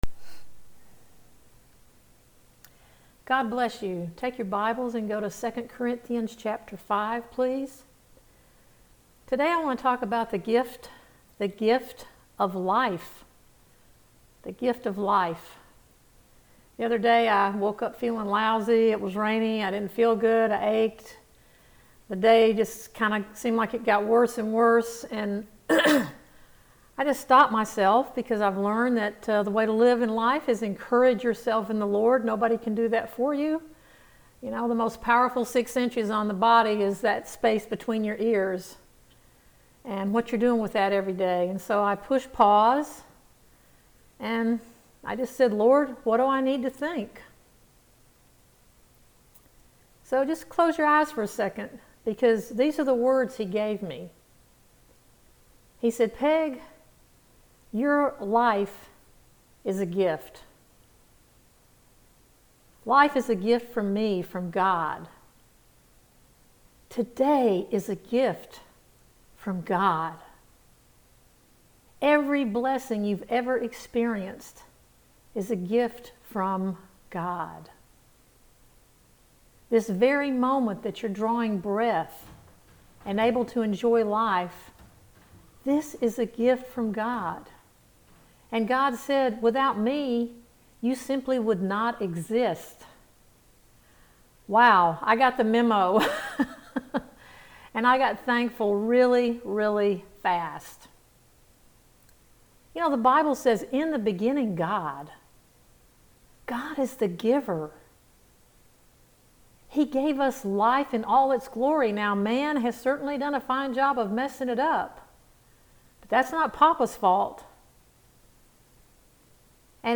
Today we have a Wonderful opportunity to hear a sharing that dives into the Amplified Bible to gain a better understanding of our Lord and Savior Christ Jesus. This is a really enriching teaching that has so much substance you will most likely desire to hear over and over.